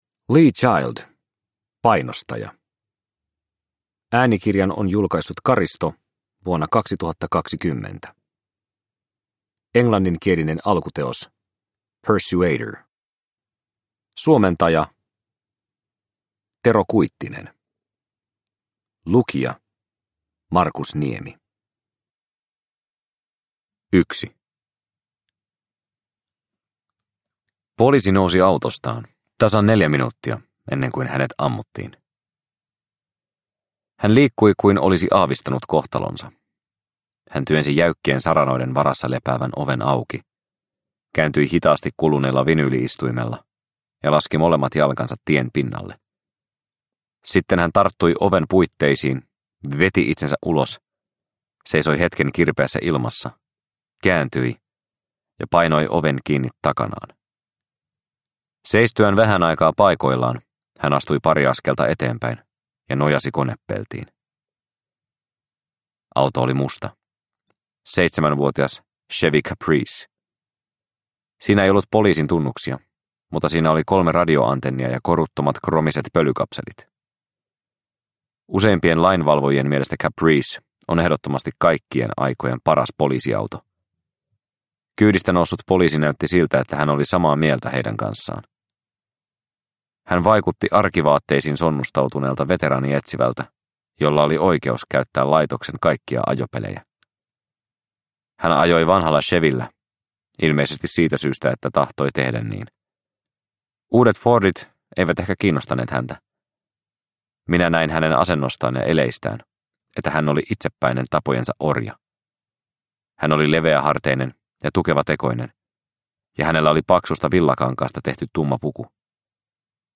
Painostaja – Ljudbok – Laddas ner